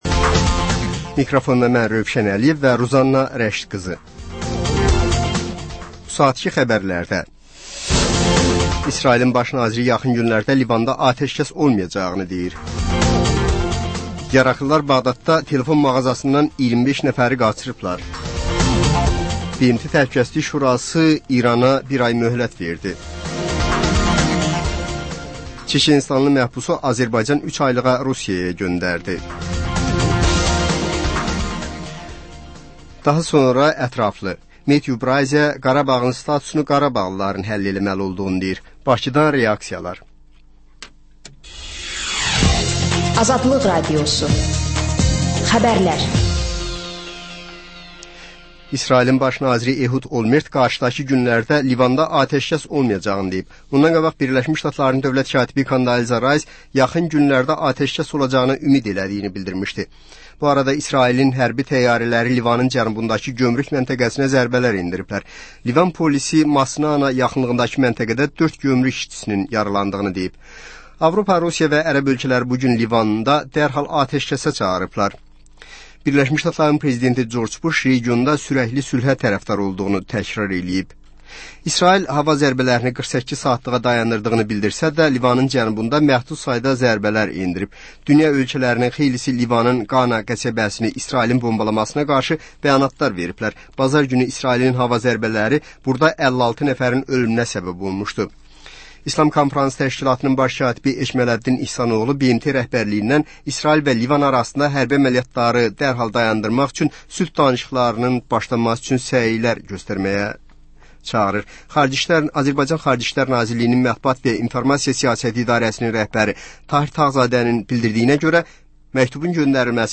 Xəbərlər, reportajlar, müsahibələr. Və sonda: Azərbaycan Şəkilləri: Rayonlardan reportajlar.